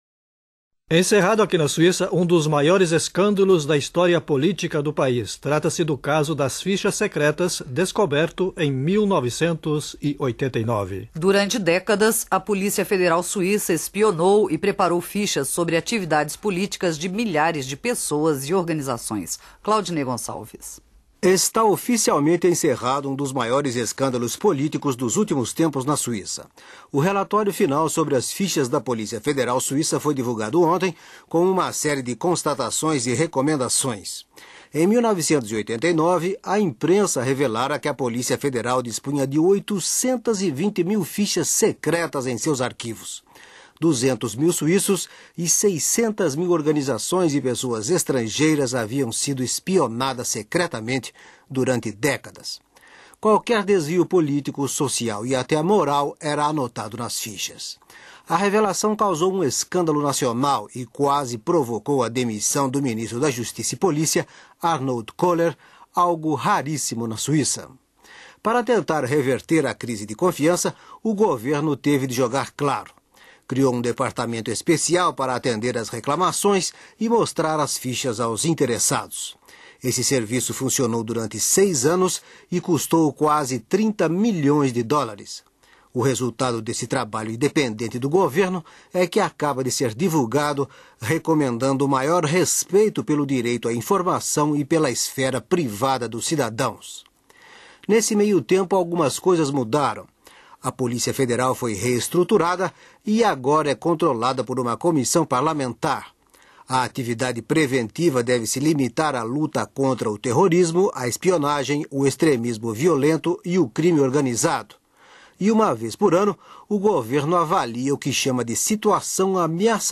Ouça o fim do escândalo das fichas dos anos 1990. Arquivo da Rádio Suíça Internacional, junho de 1996.